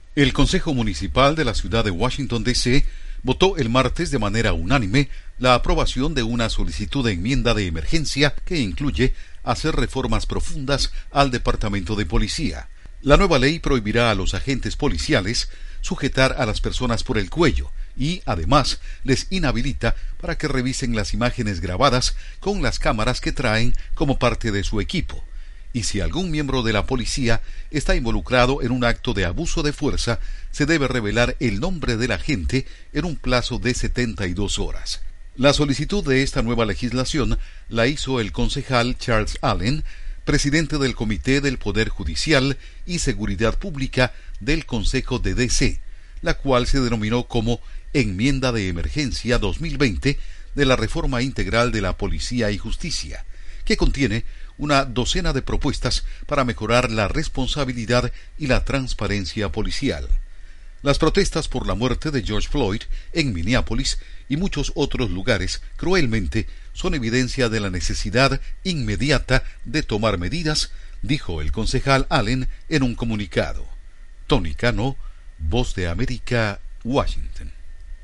Consejo Municipal de la capital de Estados Unidos aprueba realizar reformas al departamento de policía. Informa desde la Voz de América en Washington